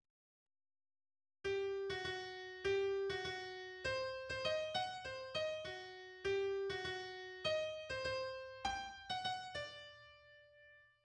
MIDI audio file